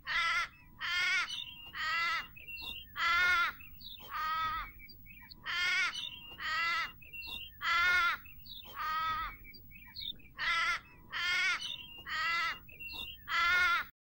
Звуки кенгуру
Кенгурёнок громко кричит